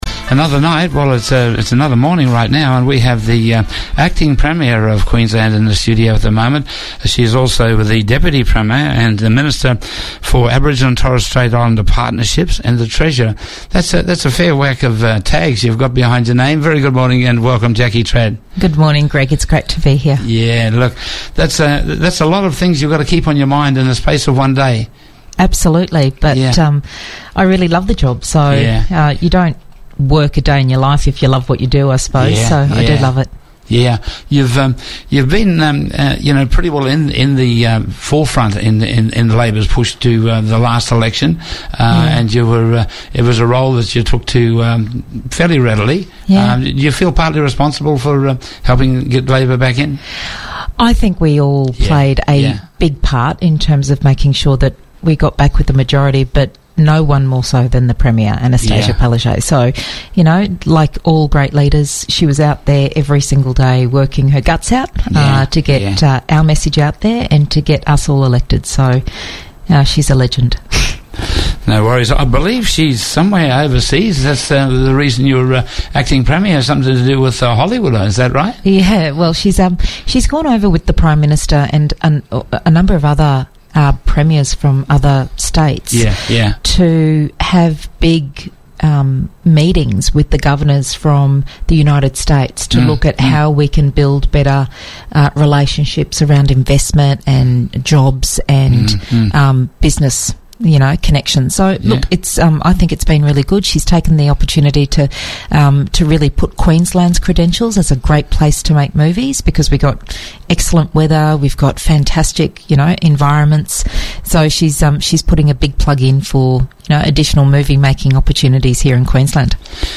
Ms Trad also visited Black Star Studios in Cairns today